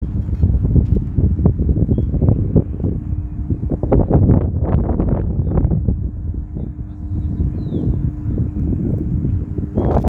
Grey Monjita (Nengetus cinereus)
Country: Uruguay
Condition: Wild
Certainty: Observed, Recorded vocal
Monjita-gris_1_2.mp3